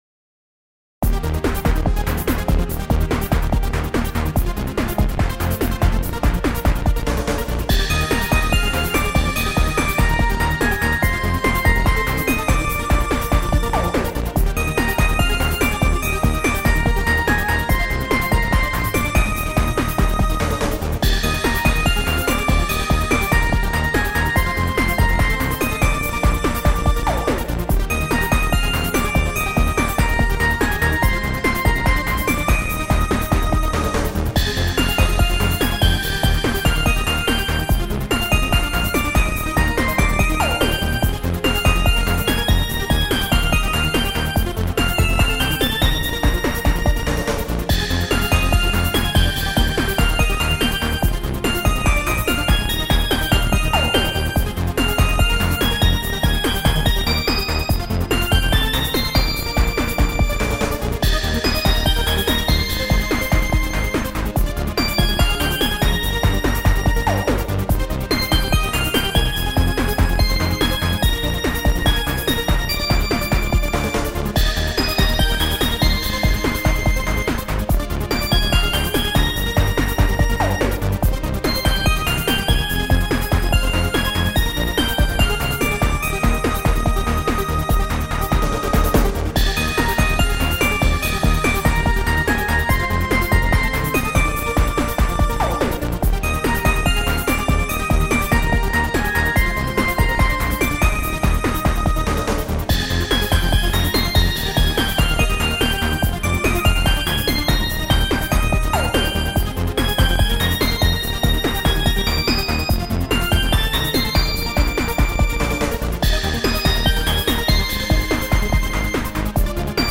エレクトロニカロング明るい